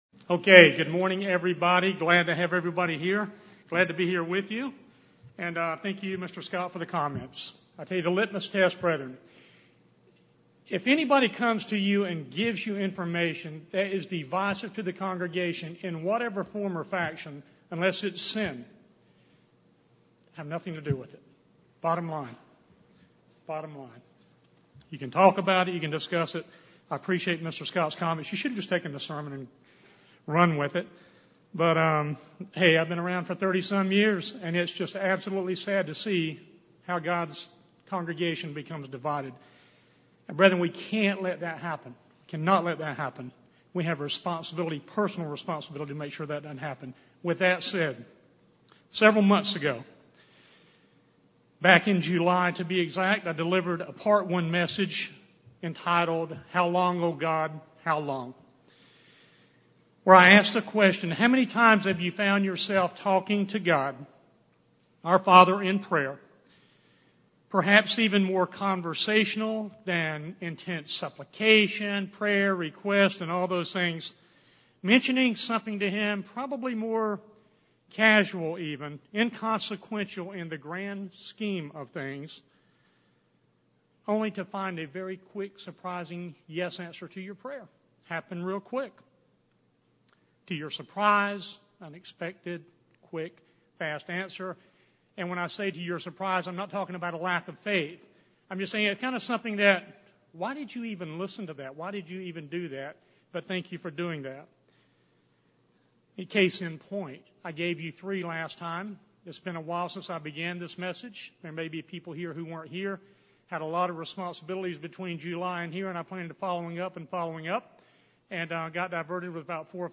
Given in Raleigh, NC
UCG Sermon Studying the bible?